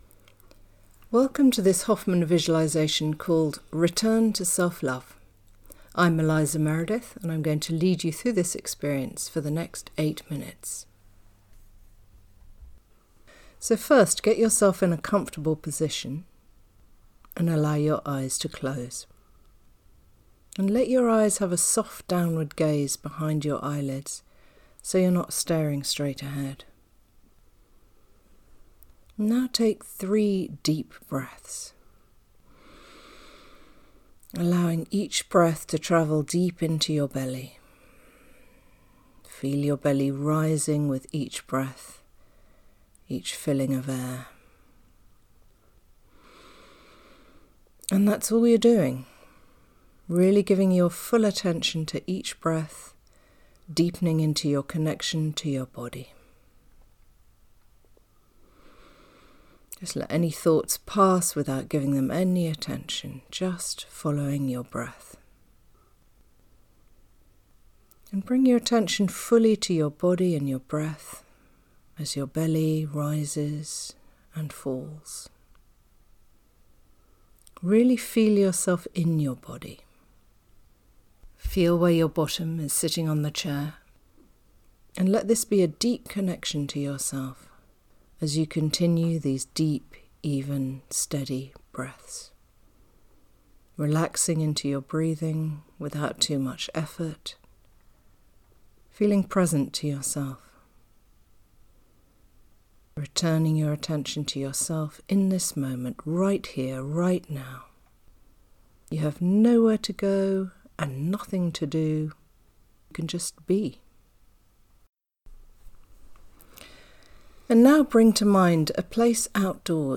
Creating spaces in your day for stillness is a great practice for self-nurture. Treat yourself to a few moments just for you, with this seven-minute visualisation – you’re worth it!